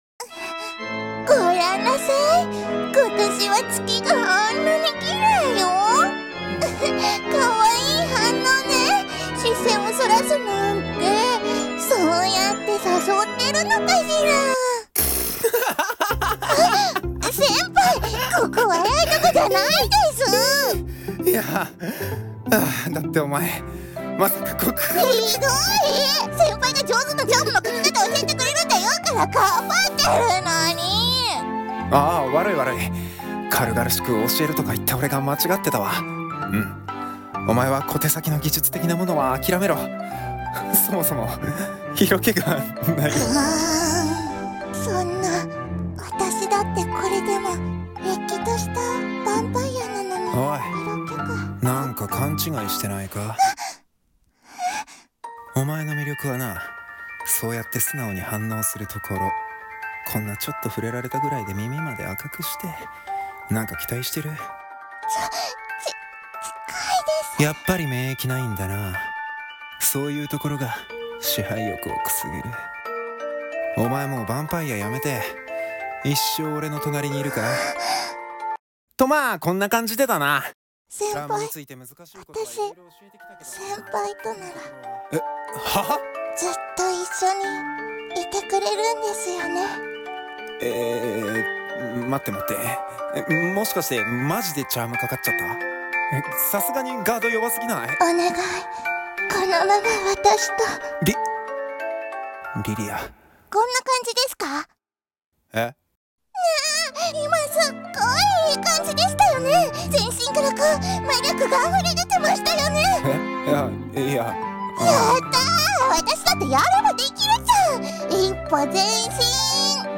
【二人声劇】純情★ヴァンパイア【アニメ予告風台本】